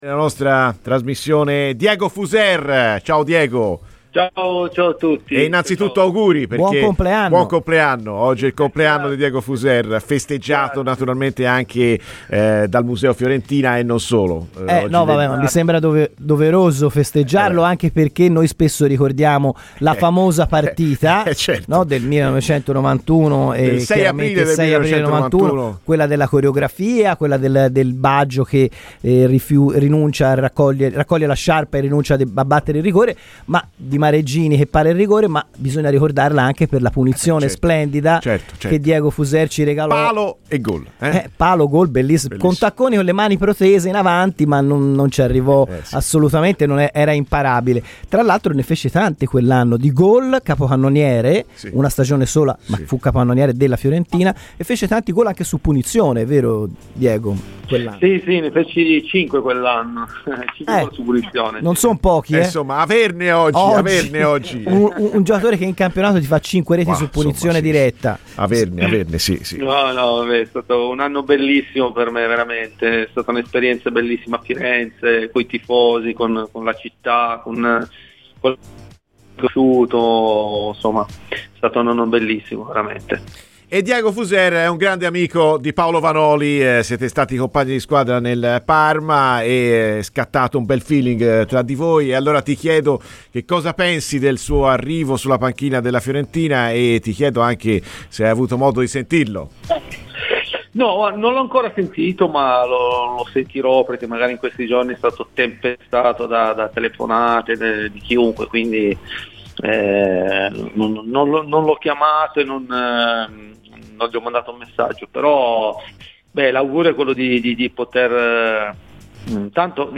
L'ex Fiorentina, Diego Fuser, ha parlato a Radio FirenzeViola durante "Viola amore mio" dell'amico Paolo Vanoli: "Intanto mi dispiace per Pioli, dopodiché spero che Vanoli riesca a riportare la Fiorentina dove merita.